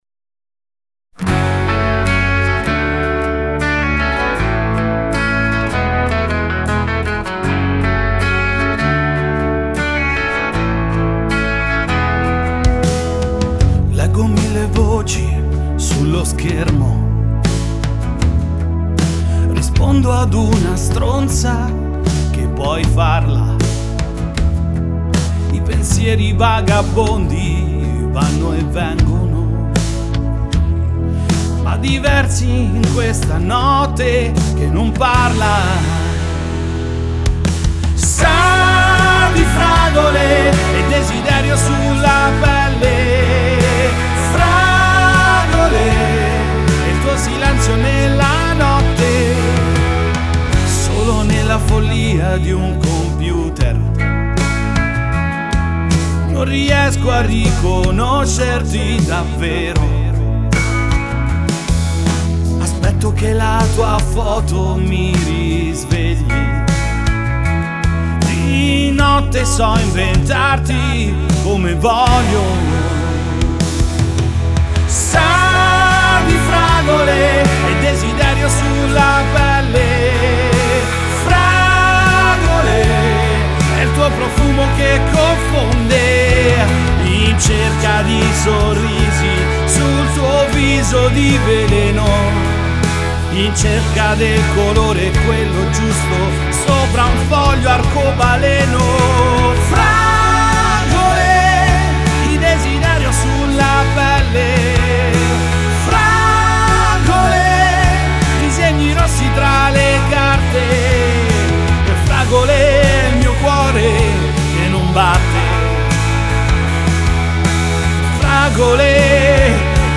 la suoneria ROCK che ti rende “Hot💋” !!!